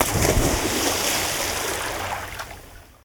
watersplash.wav